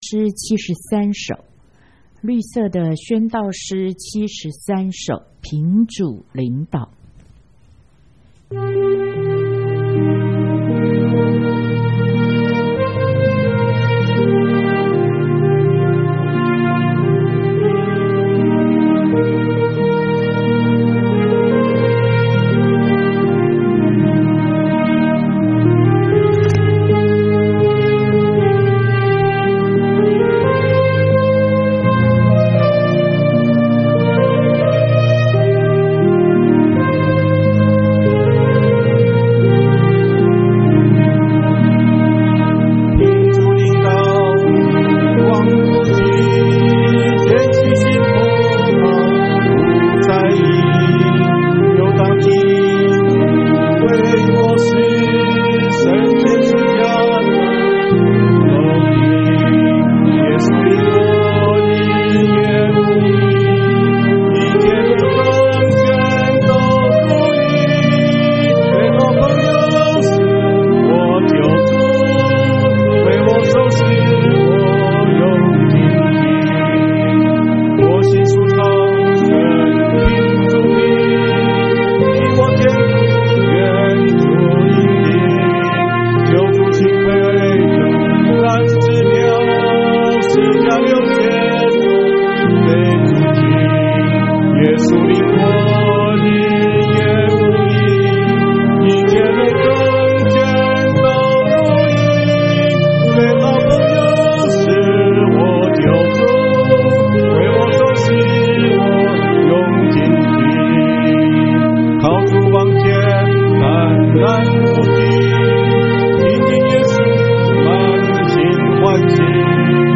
37〈宣道詩73憑主領導〉敬拜